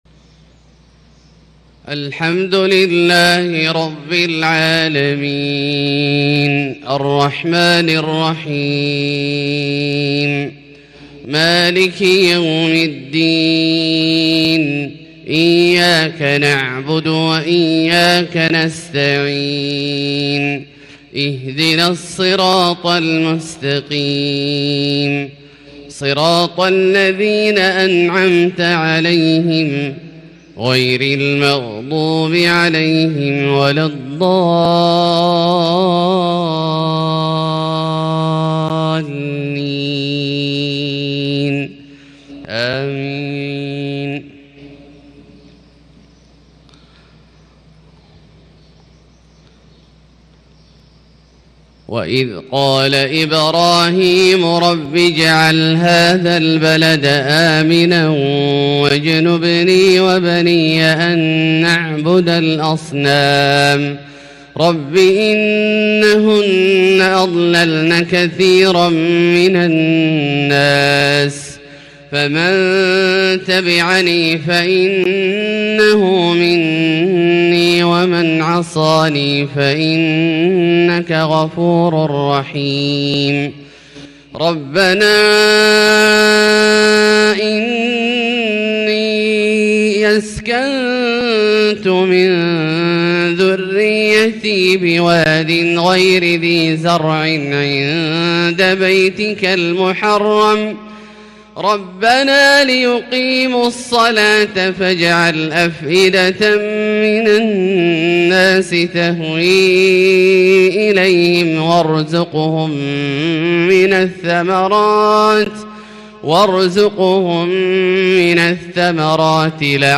صلاة الفجر 9-4-1442هـ من سورة إبراهيم 35-52 > ١٤٤٢ هـ > الفروض - تلاوات عبدالله الجهني